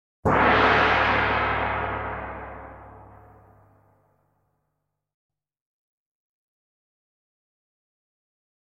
Глубокие вибрации и переливчатые обертоны подойдут для медитации, звукотерапии или создания атмосферы в творческих проектах.
Гулкий звук удара в гонг